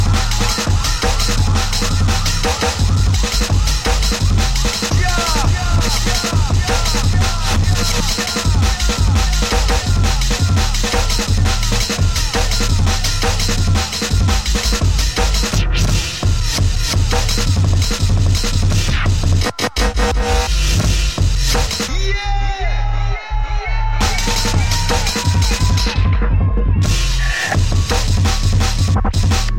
TOP >Vinyl >Drum & Bass / Jungle